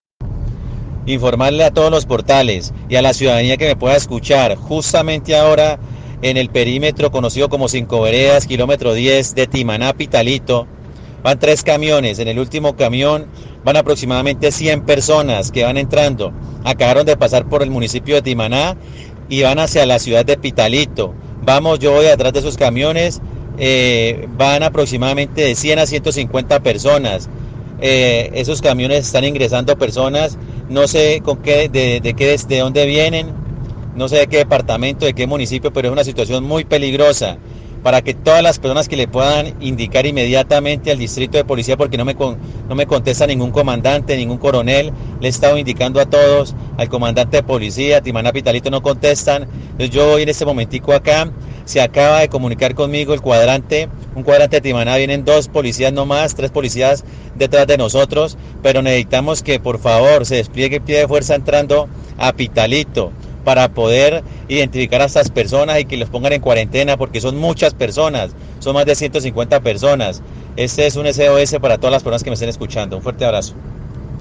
1 LLAMADO DE ANGUSTIA ALCALDE DE TIMANÁ AUDIO
AUDIOS DEL ALCALDE DE TIMANÁ CON LLAMADO DE SOS Y ADVERTENCIAS.